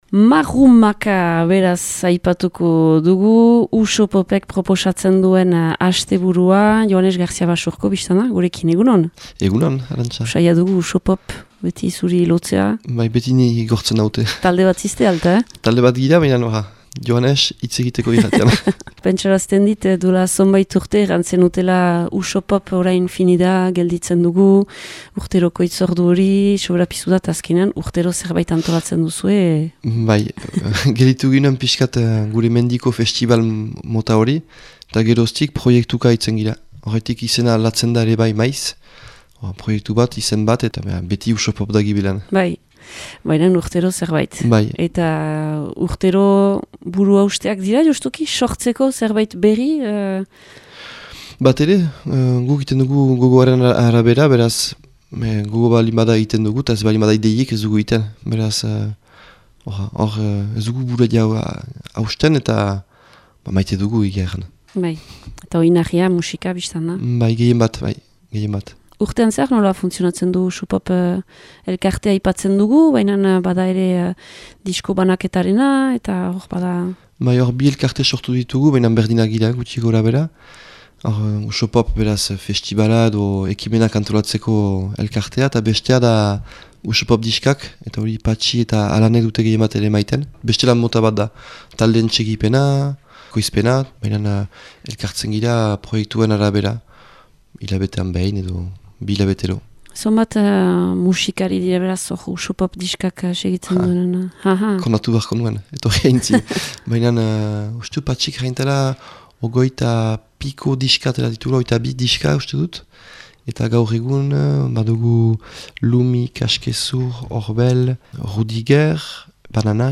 USOPOPeko kidearen elkarrizketa.